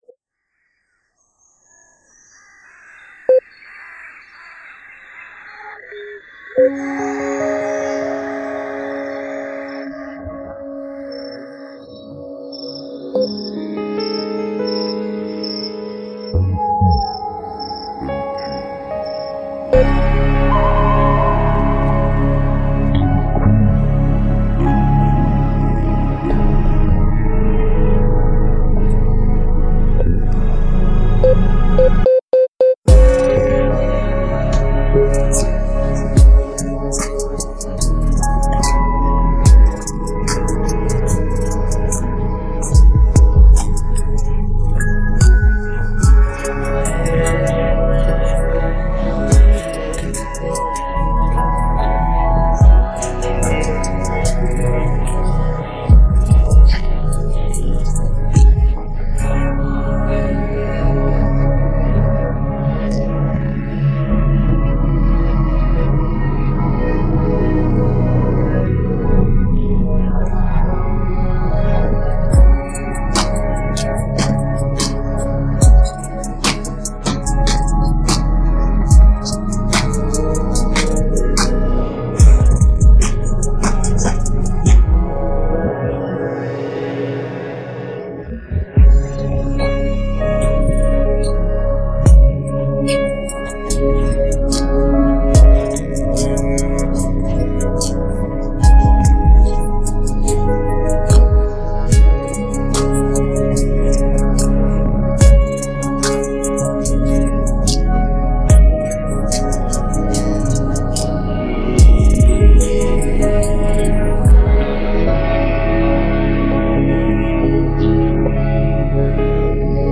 پخش نسخه بی‌کلام